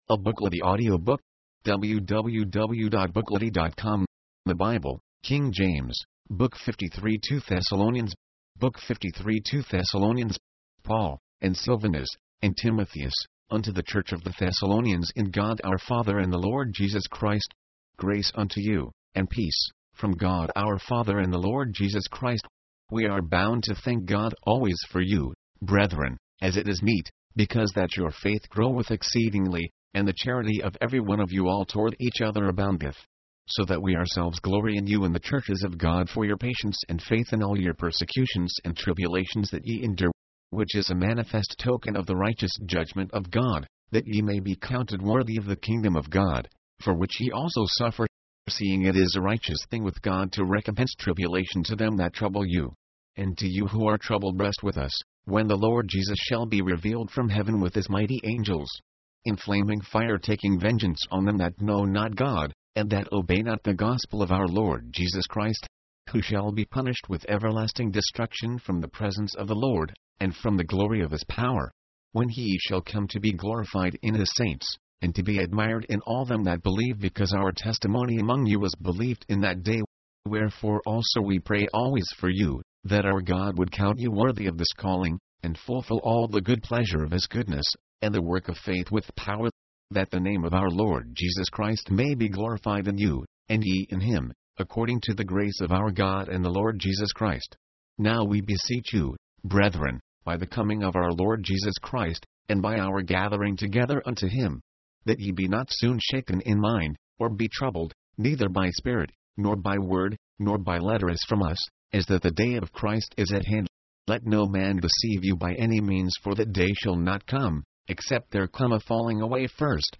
Since the end has not yet arrived, the congregation is to refrain from idleness and not to become weary in doing what is right. mp3, audiobook, audio, book